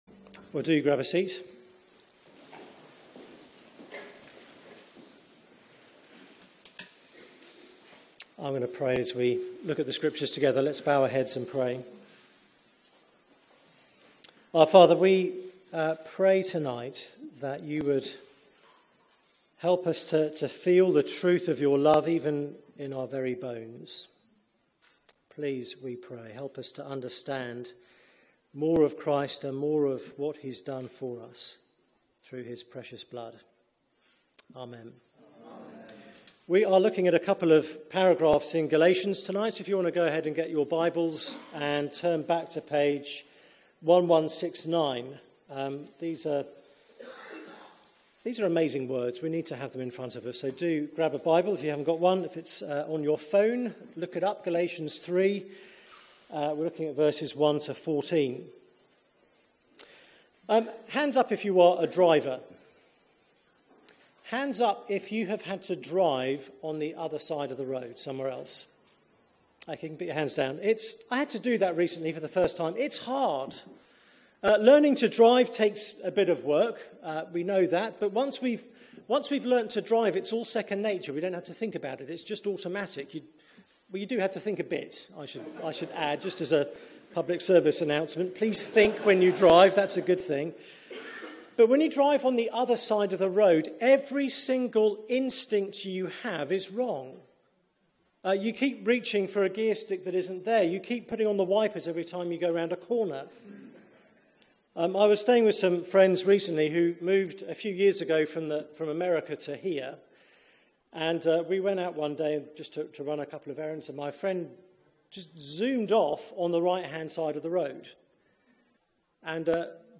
Media for 6:30pm Service on Sun 24th Sep 2017
Theme: Our blessing, His curse Sermon